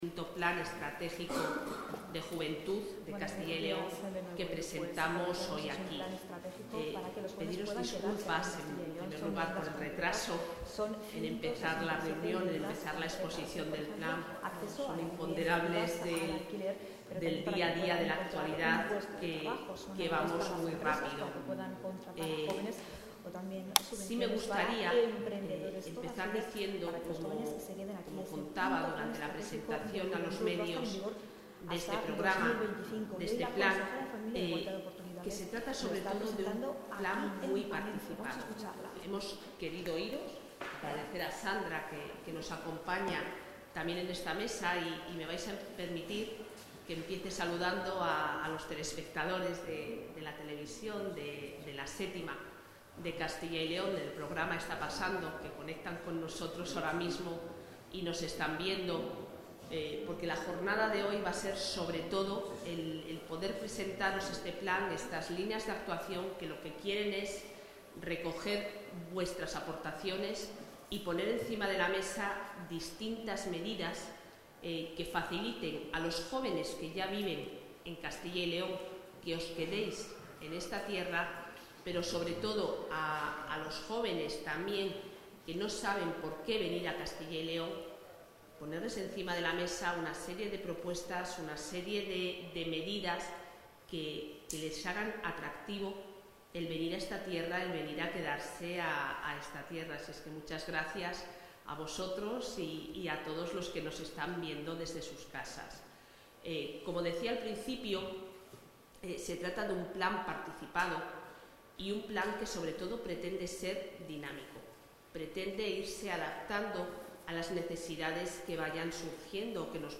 Intervención de la consejera.
La consejera de Familia e Igualdad de Oportunidades, Isabel Blanco, ha presentado esta mañana el V Plan de Juventud de Castilla y León, que será aprobado en los próximos días, en un acto celebrado en Palencia junto a colectivos, asociaciones y entidades juveniles, además de consejos locales, provinciales y el Consejo Autonómico de la Juventud.